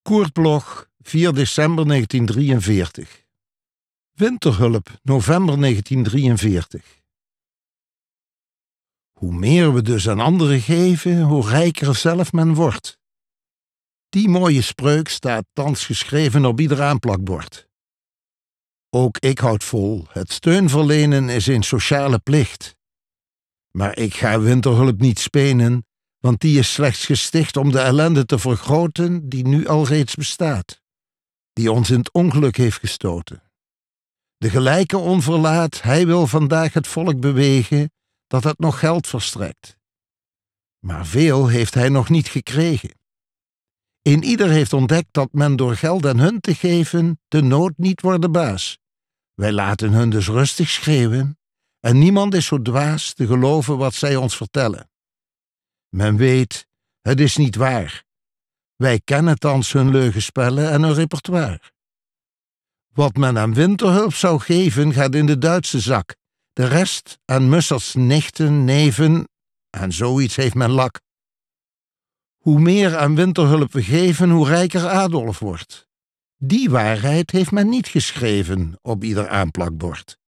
Aufnahme: MOST, Amsterdam · Bearbeitung: Kristen & Schmidt, Wiesbaden